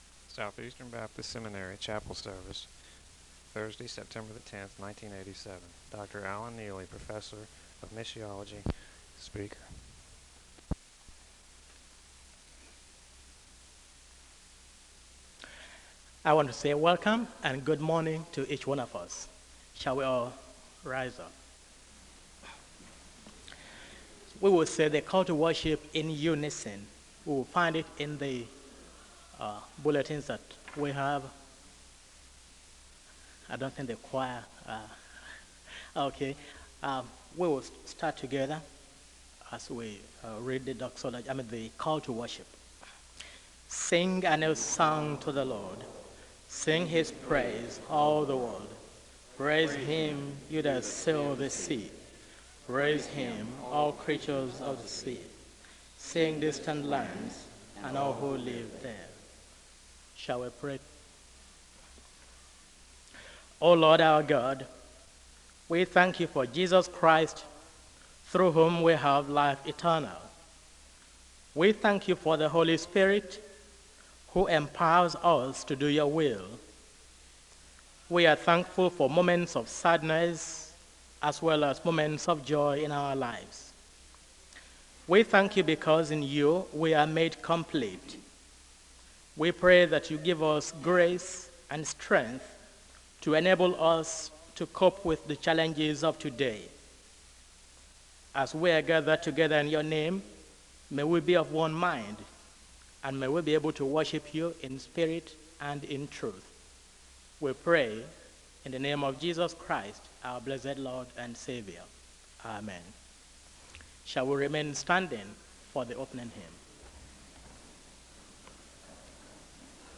The service begins with a welcome and a call to worship (0:00-0:59). There is a moment of prayer (1:00-1:52)....
There is a Scripture reading from Jonah 3-4 (1:53-4:20).